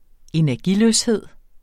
Udtale [ enæɐ̯ˈgiˌløsˌheðˀ ]